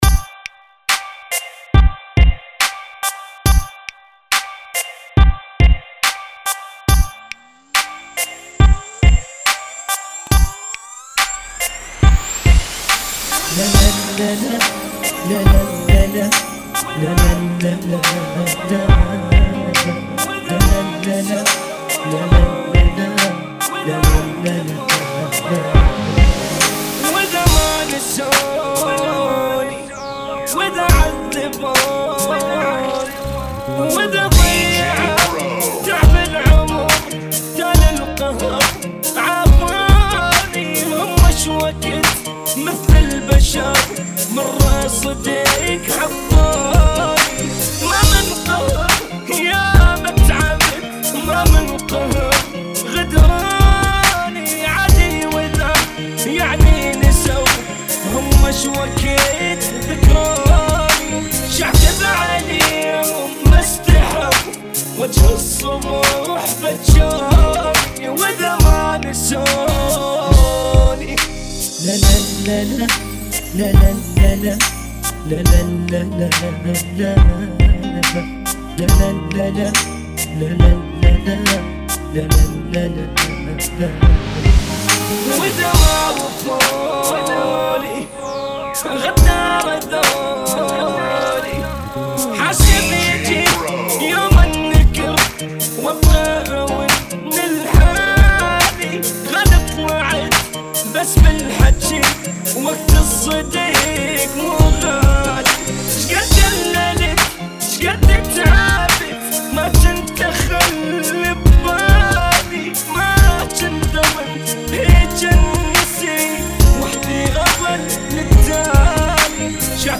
070 bpm